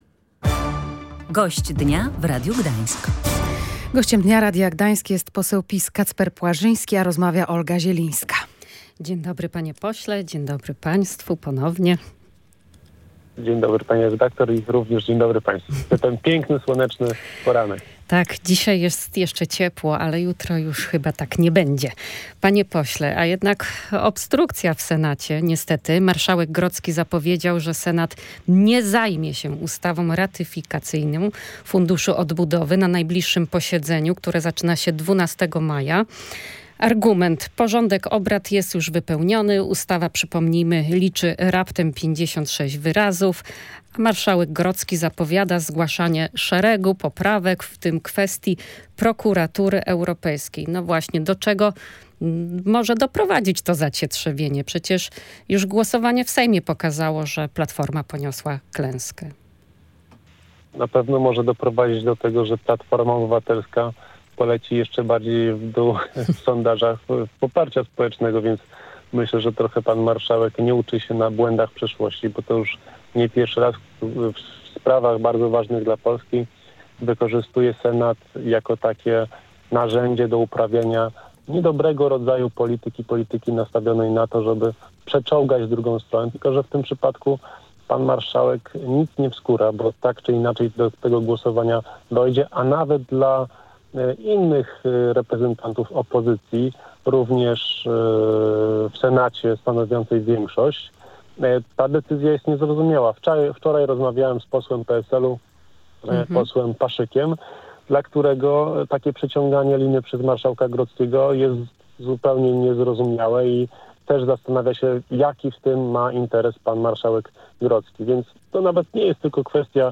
Przeciąganie przez Senat przyjęcia Planu Odbudowy to działanie na szkodę kraju - mówił w Radiu Gdańsk poseł Prawa i Sprawiedliwości